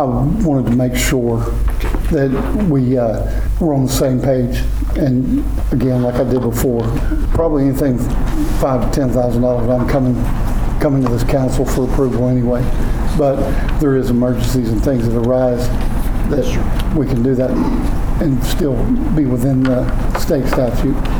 At a specially held city council meeting in Pawhuska on Tuesday evening, City Manager Jerry Eubanks was asking that his spending limit on capital expenditures be set up to $50,000.